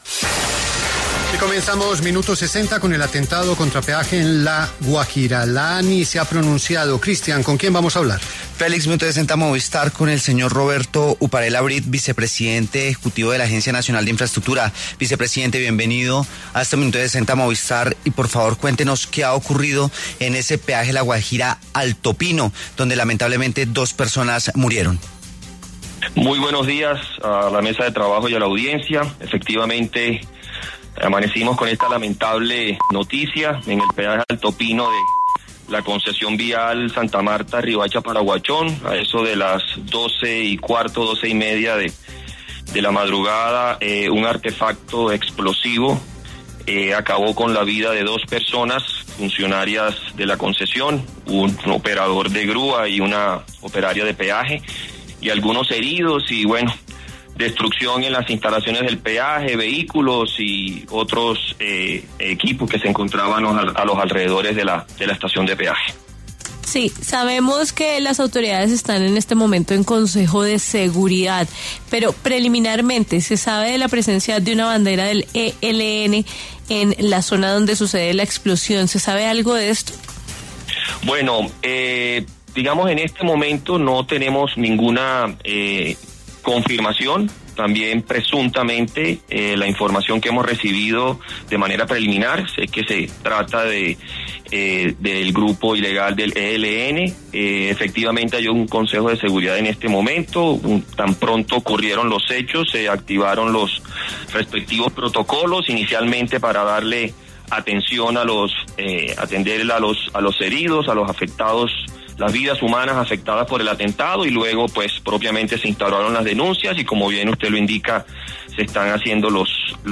En entrevista con La W, el vicepresidente ejecutivo de la ANI, Roberto Uparela Brid, confirmó que en el peaje en mención se encontró una bandera del ELN; sin embargo, reiteró que hasta el momento las autoridades están evaluando e iniciando investigaciones.